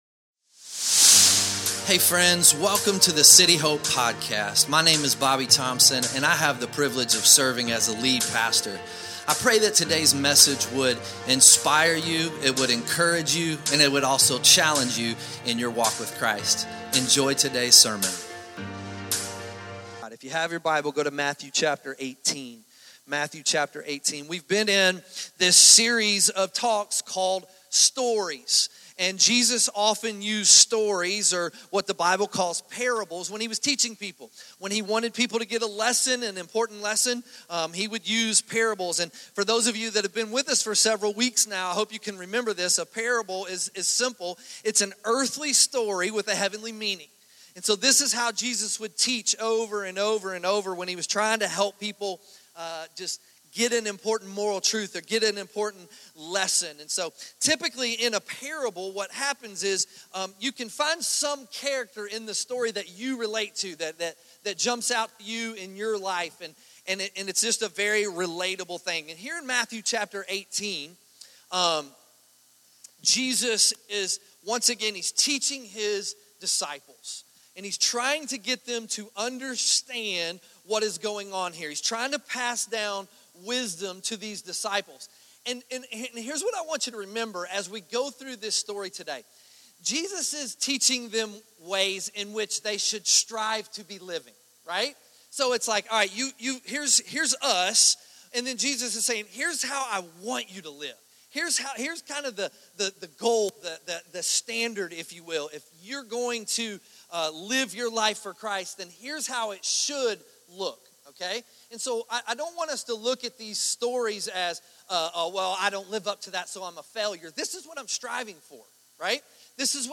2025 Forgiveness Sunday Morning The Unforgiving Servant